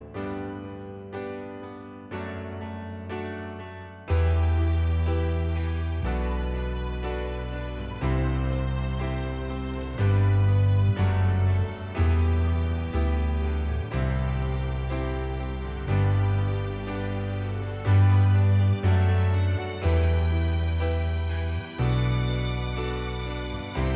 Minus Guitars Soft Rock 3:28 Buy £1.50